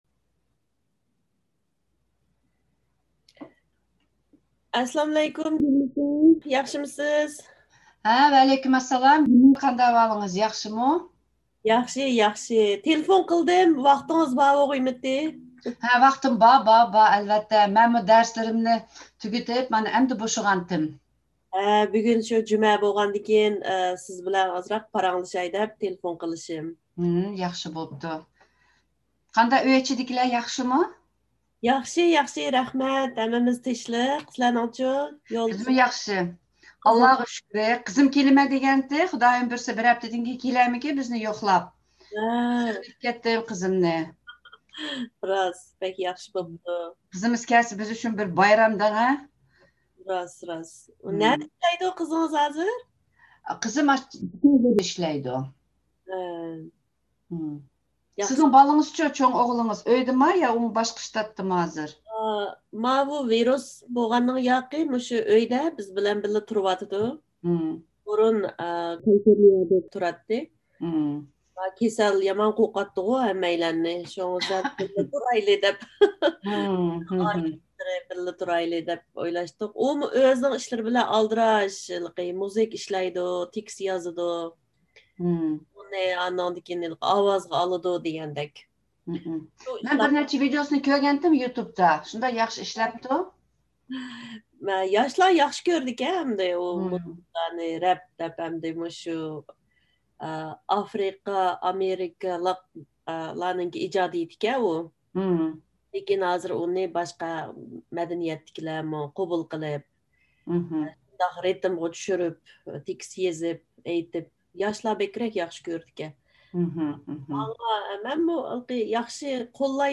Conversation 1
Conversation 1 Download audio file: Conversation1.mp3 Download transcript file: Conversation1.eaf Download XML: Conversation1.xml View Online: Conversation 1 Two colleagues who both teach Uyghur language classes talk over Zoom about work, family, and a variety of other topics.